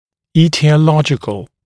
[ˌiːtɪə’lɔʤɪkl][ˌи:тиэ’лоджикл]этиологический